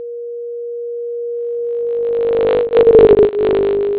Generate the stereo signals appropriate for setups (1) and (2).
Velocity of object: 25m/s
Sound generated by source: 440Hz tone
It actually sounds like the object is moving past you. The combination of the changing frequency and loudness, as well as the
There are still artifacts, but the doppler effect is very apparent.